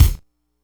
kick02.wav